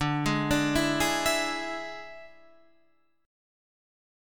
D Suspended 2nd Flat 5th